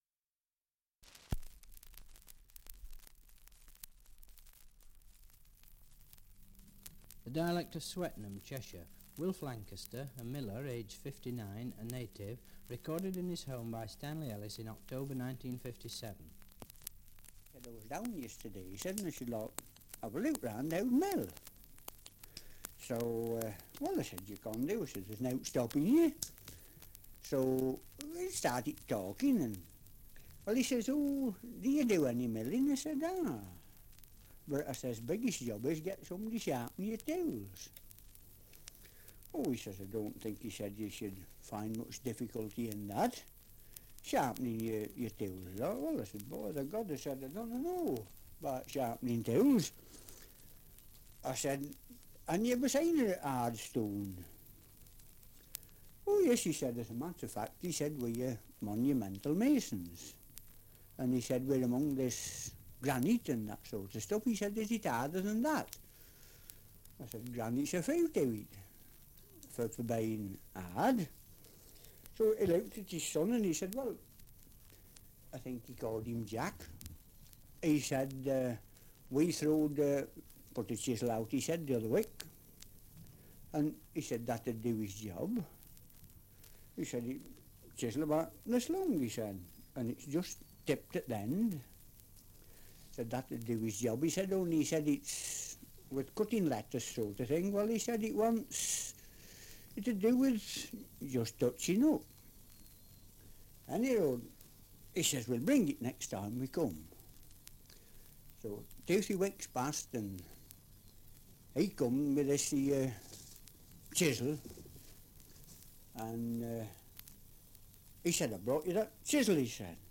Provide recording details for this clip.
Survey of English Dialects recording in Swettenham, Cheshire 78 r.p.m., cellulose nitrate on aluminium